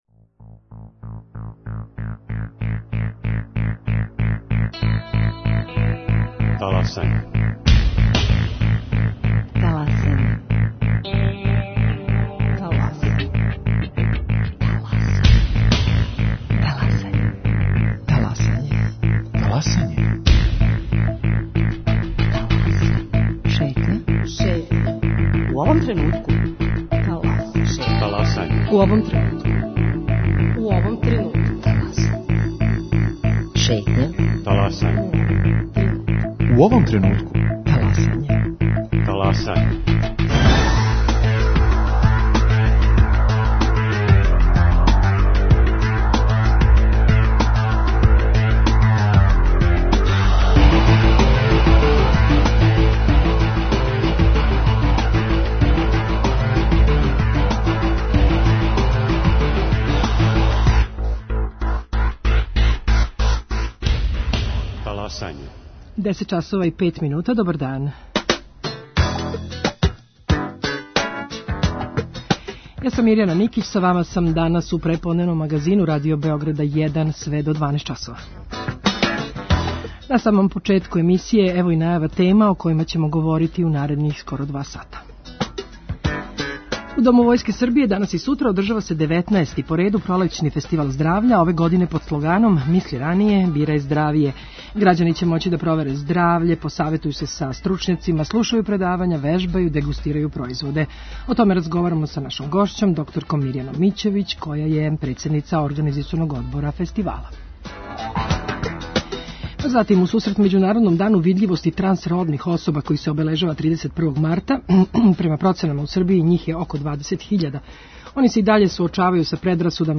У сусрет 95-ој годишњици Радио Београда представљамо најпознатије емисије које сте волели.